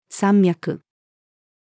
山脈-female.mp3